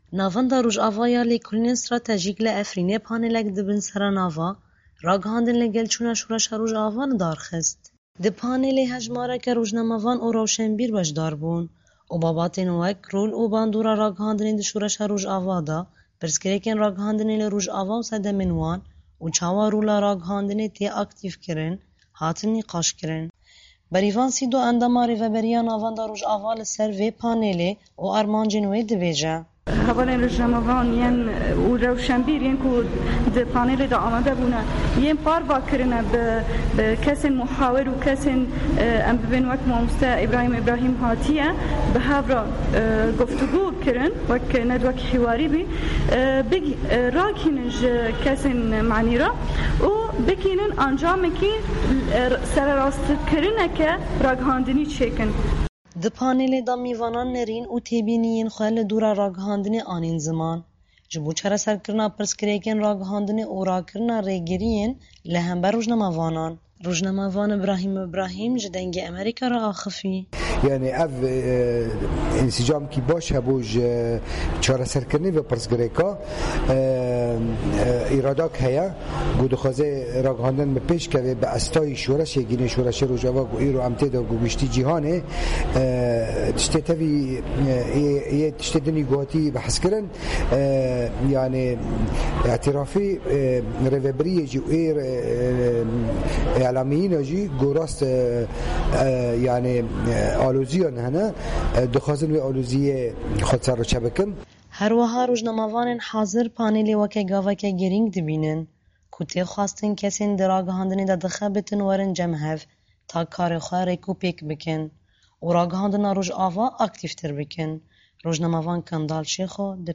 Navenda Rojava ya Lêkolînên Stratejîk li Efrînê paneleke bi sernavê (Ragihandin ligelçûna Şoreşa Rojava) de, li dar xist.